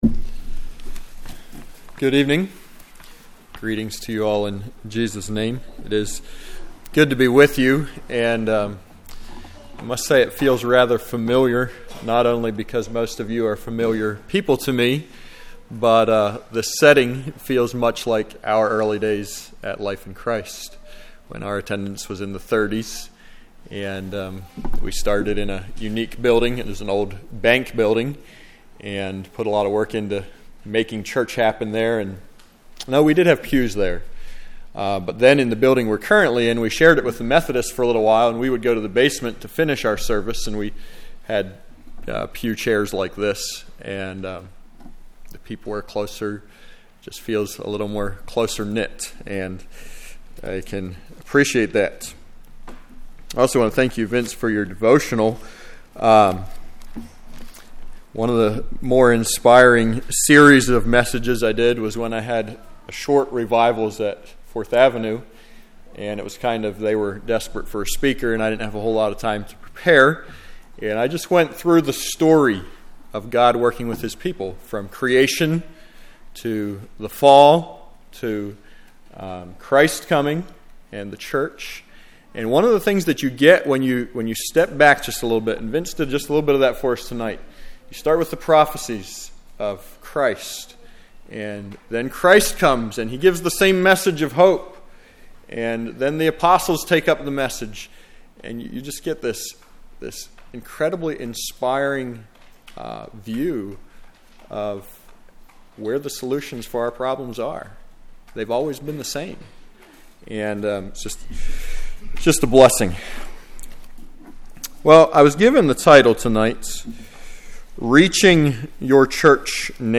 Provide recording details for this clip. Congregation: Hidden River